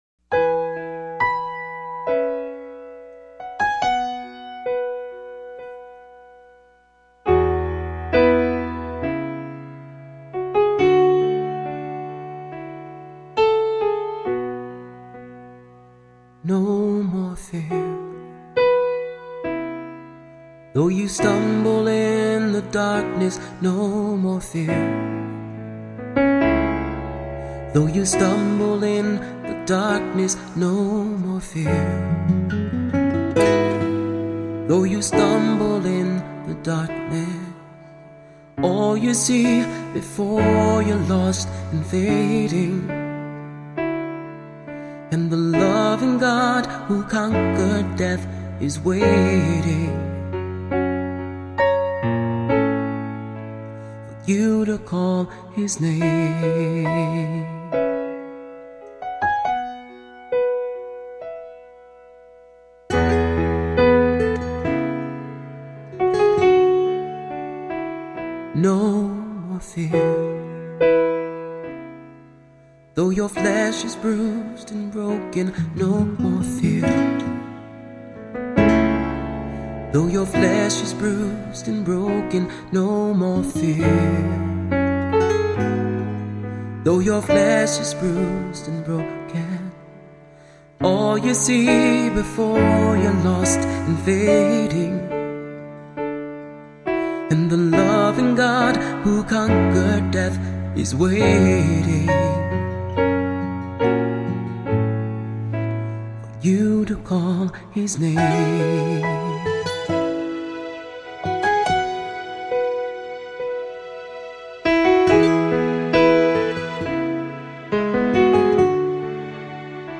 Voicing: Soloist or Soloists